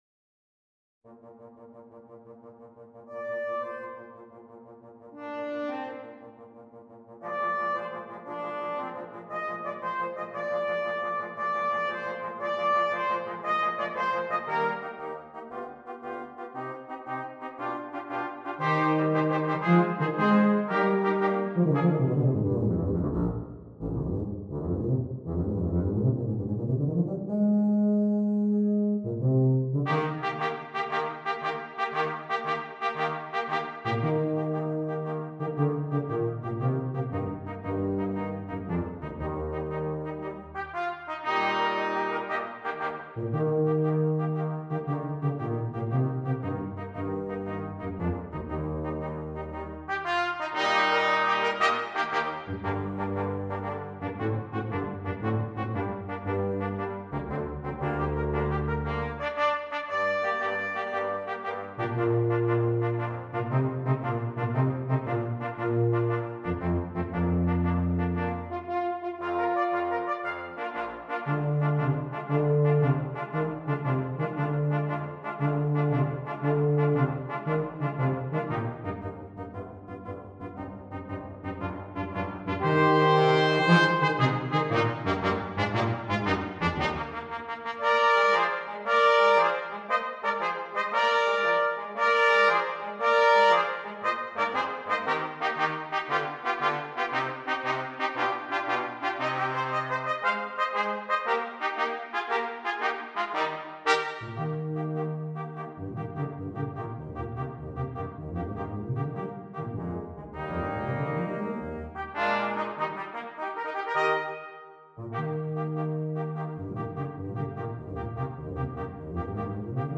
Brass Quintet
brass quintet featuring the tuba player